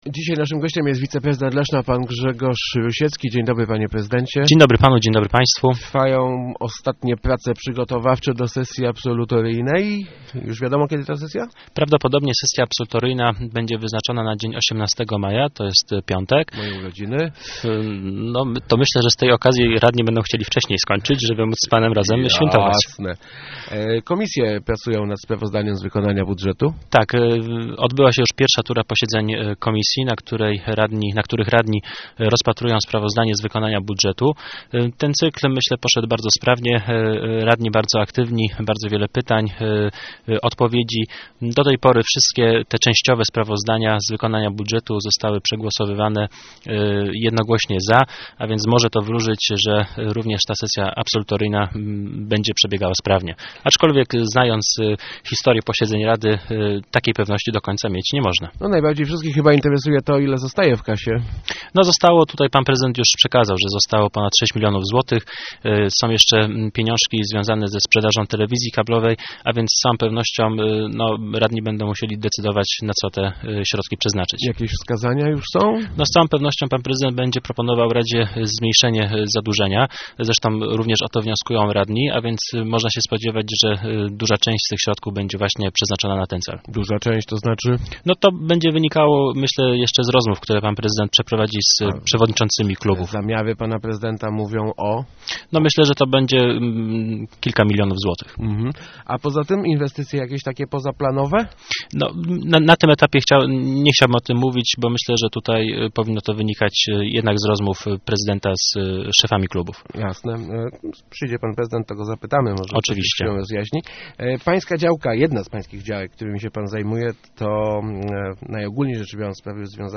Gościem Kwadransa Samorządowego był wiceprezydent Grzegorz Rusiecki.